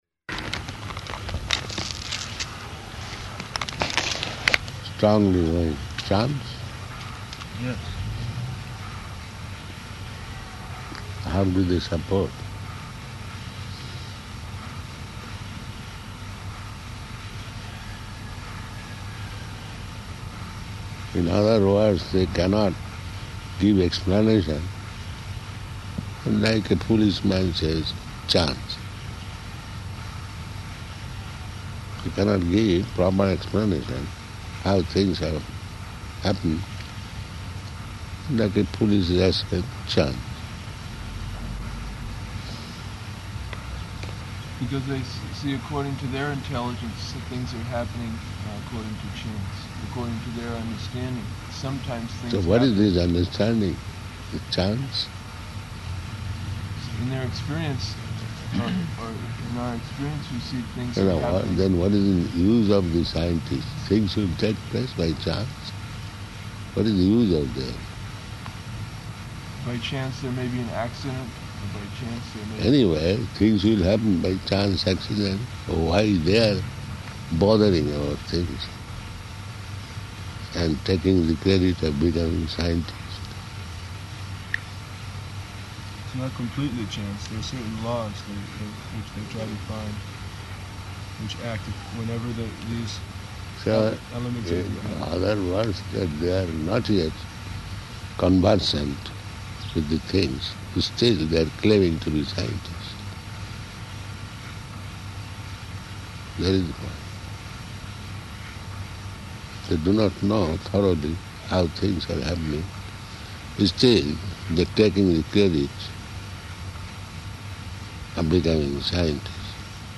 Room Conversation
Room Conversation --:-- --:-- Type: Conversation Dated: August 11th 1976 Location: Tehran Audio file: 760811R1.TEH.mp3 Prabhupāda: ...strongly by chance?